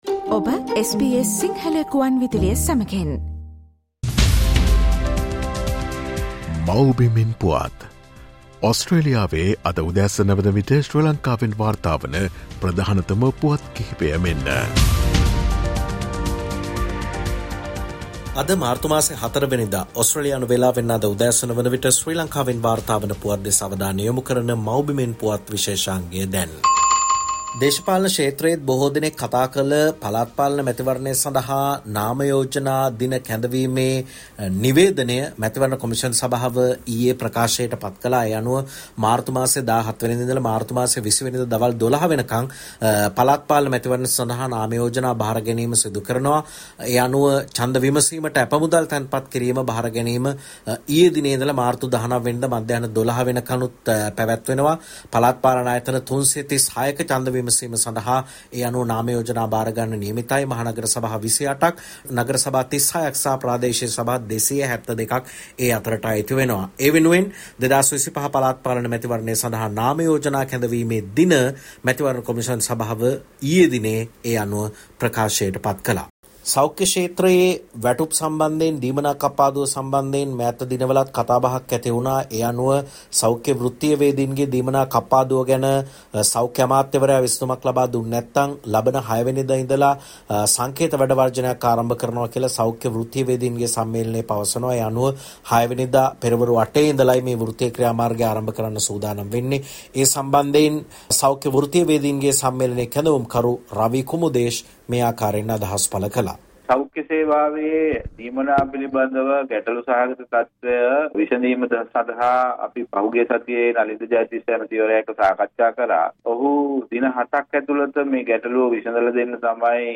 presents the latest news highlights from Sri Lanka today, March 04th.